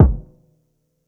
Jazz Kick 3k.wav